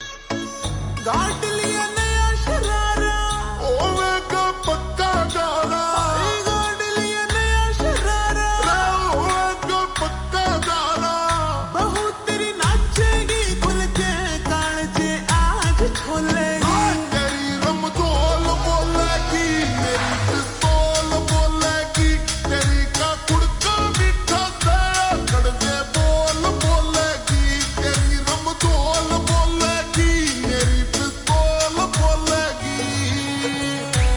Haryanvi Songs
(Slowed + Reverb)